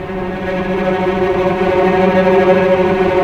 Index of /90_sSampleCDs/Roland LCDP08 Symphony Orchestra/STR_Vcs Bow FX/STR_Vcs Trem wh%